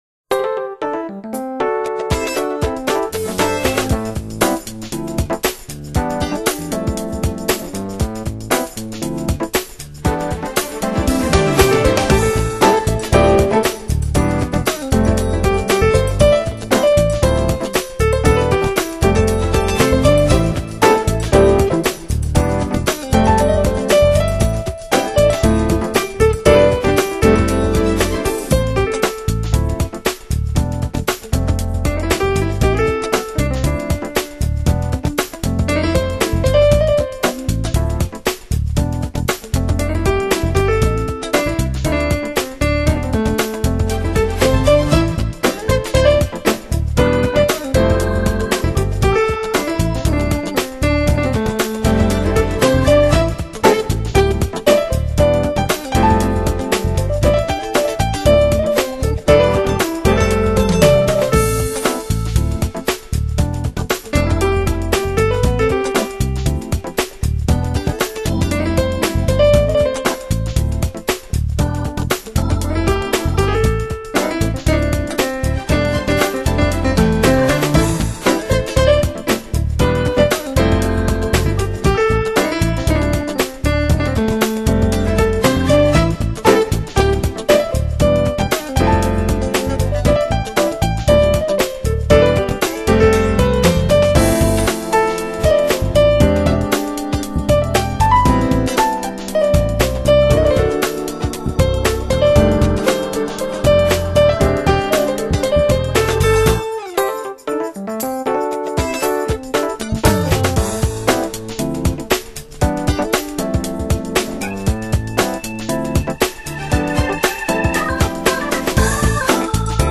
有钢琴还有吉他，当然伦敦地铁也就成了时尚都市的风景线了。